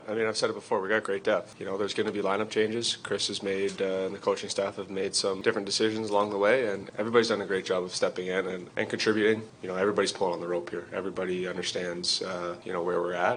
Captain Connor McDavid spoke about his teams depth and also about how getting to the Stanley Cup Finals is definitely an effort by the entire bench: